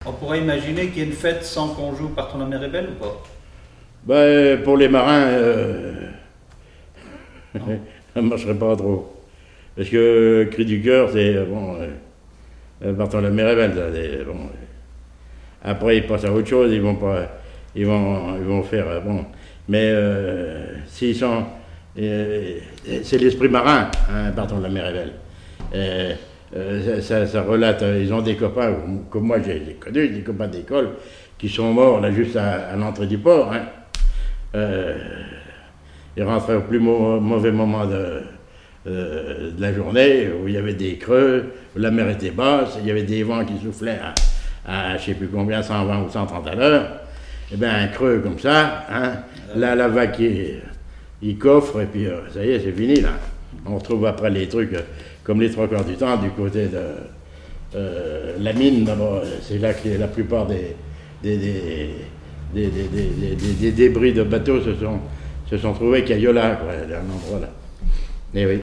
Témoignage sur la musique et des airs issus du Nouc'h